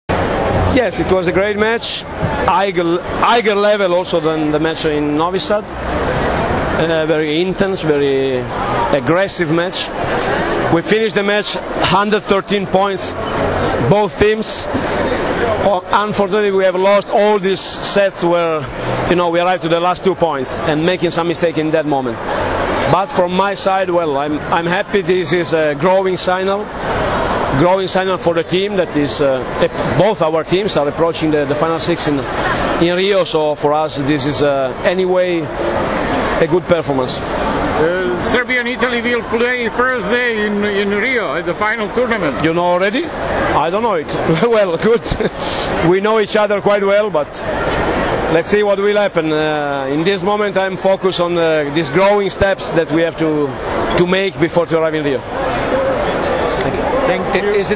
IZJAVA MAURA BERUTA